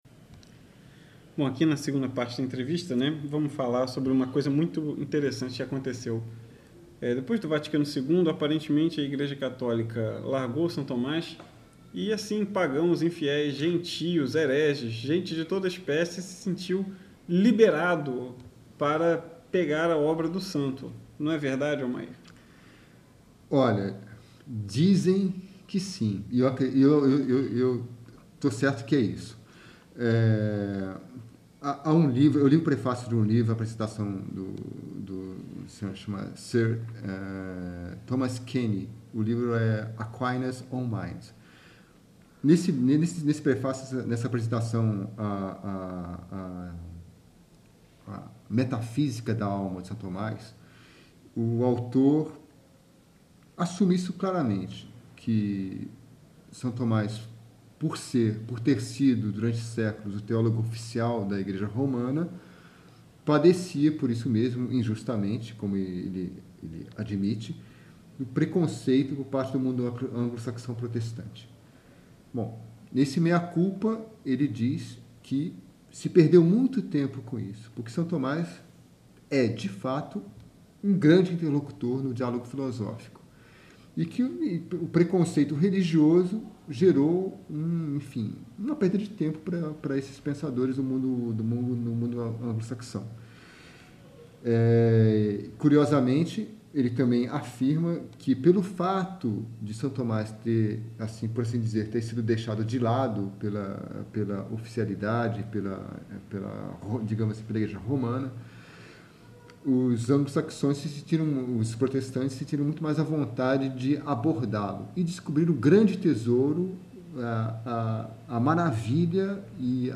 uma entrevista